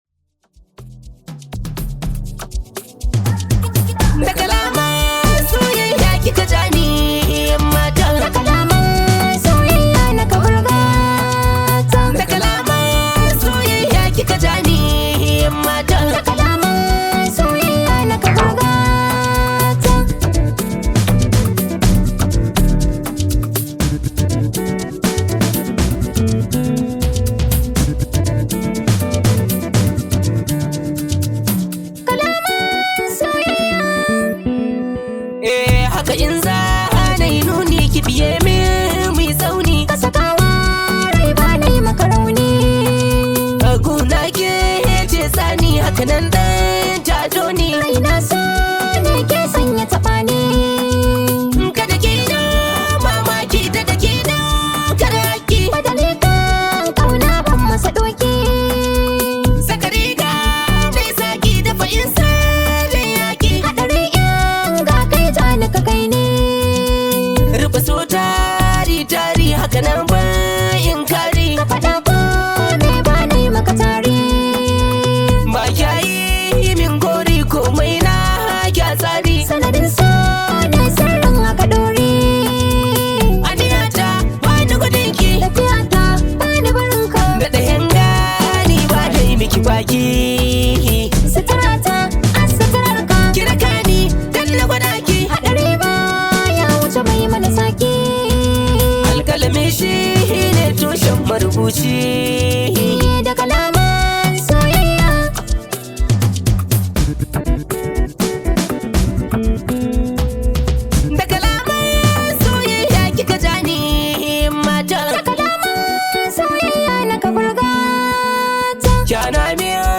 top rated Nigerian Hausa Music artist
high vibe hausa song